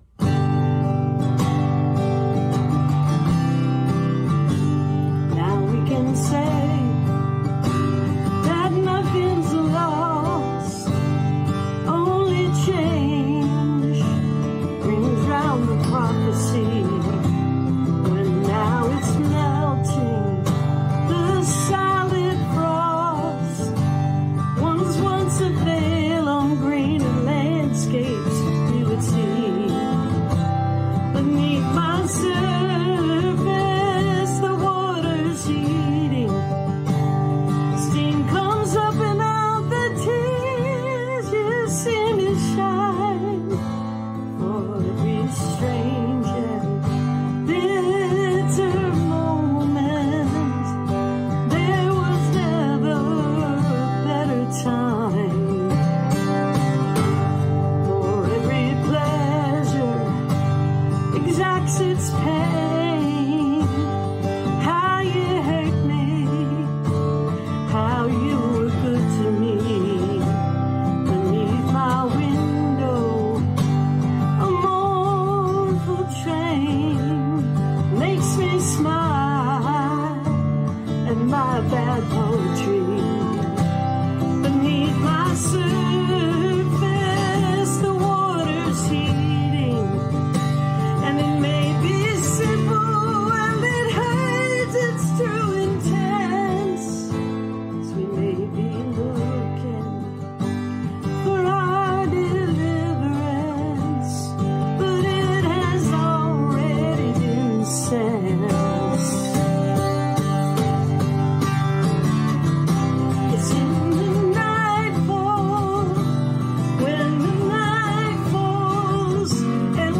(captured from the youtube video stream)